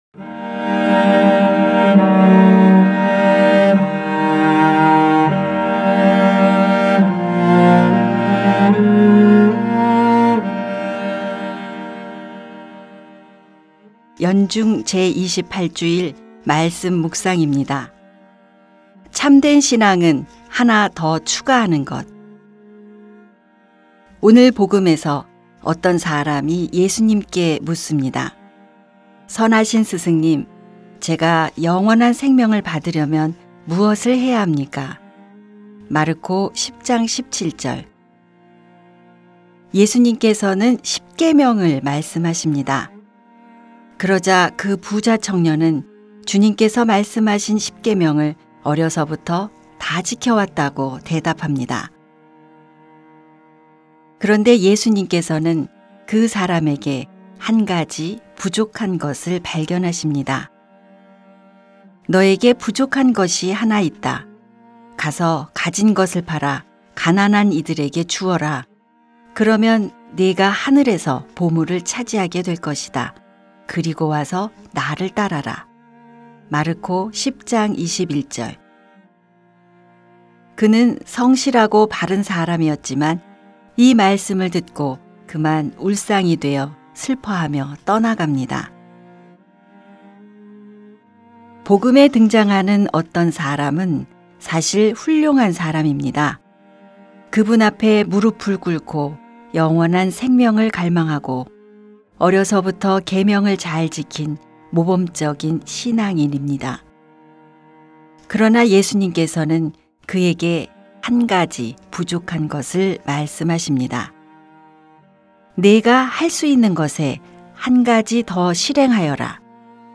2021년 10월 10일 연중 제28주일 - 말씀묵상 듣기(☜파란색 글씨를 클릭하세요)